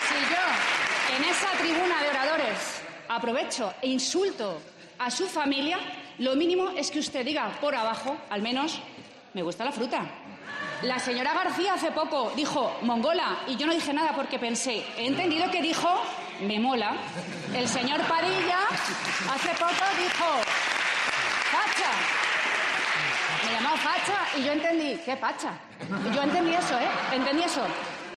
La presidenta de la Comunidad de Madrid ha defendido en la Asamblea de Madrid su exabrupto al presidente "aprovechó su intervención para difamarme a mí y a mi familia"